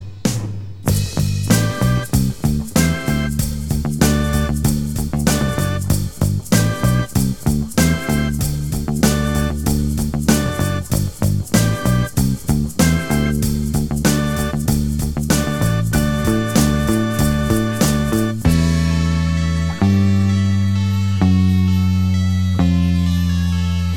Minus Guitars Pop (1960s) 3:34 Buy £1.50